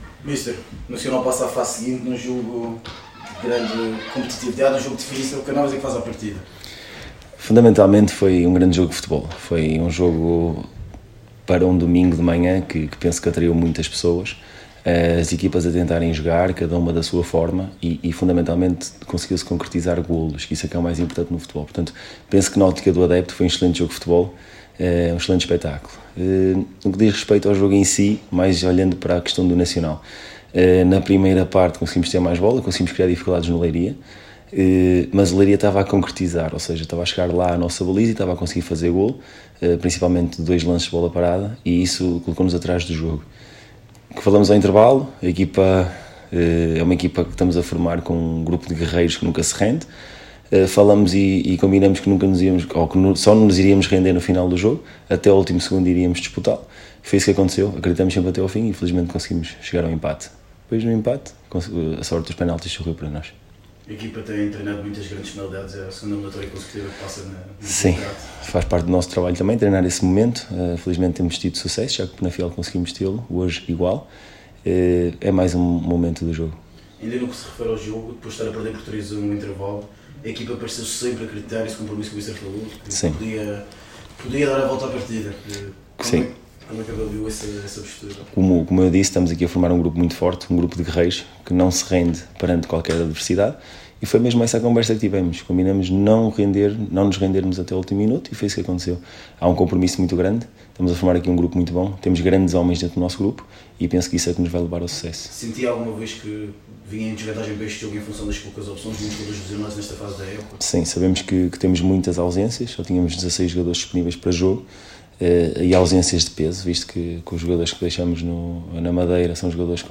Na conferência de imprensa realizada no final do encontro em Leiria